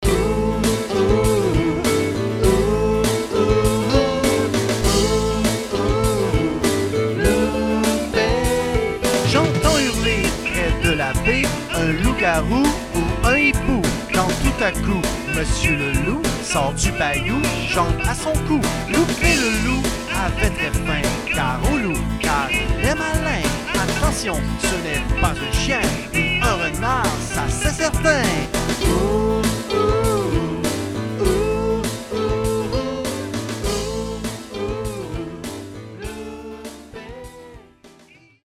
en mimes et en chansons
tout en alternant le mime, la musique et le théâtre.